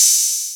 OPENHAT (STARGAZING)(1).wav